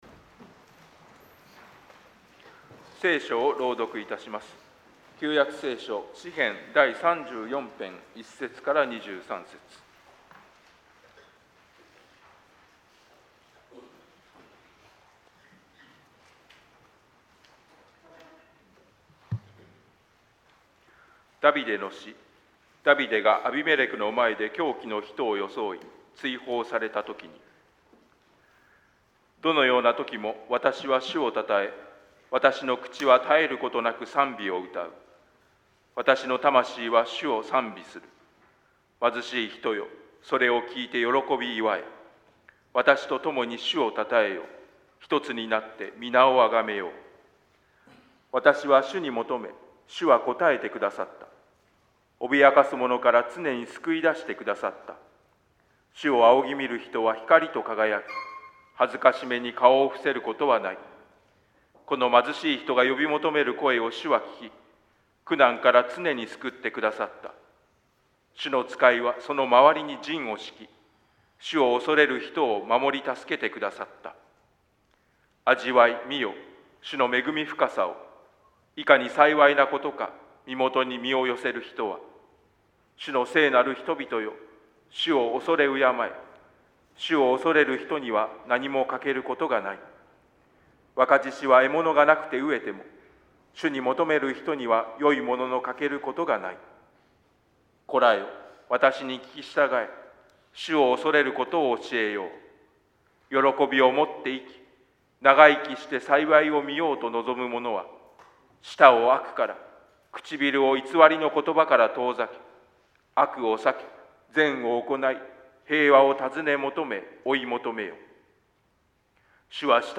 2025年9月7日主日礼拝.mp3